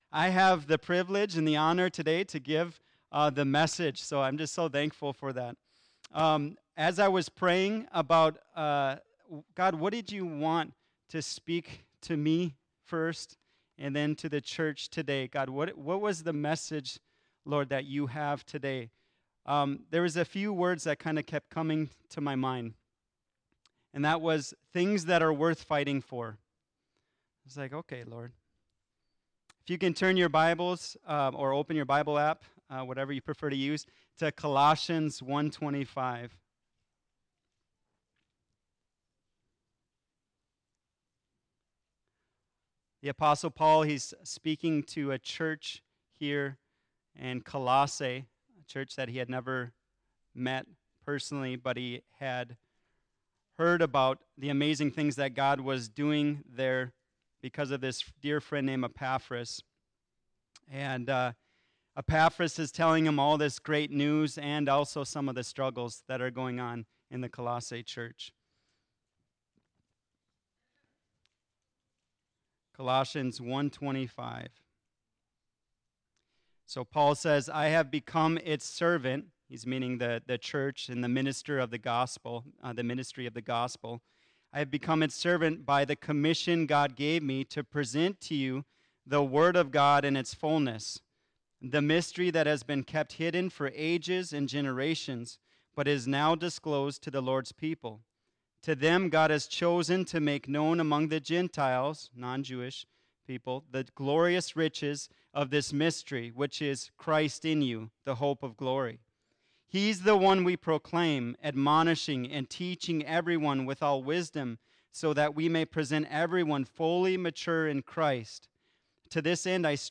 2025 Worth Fighting For Preacher